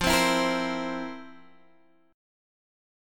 Listen to FM7sus4 strummed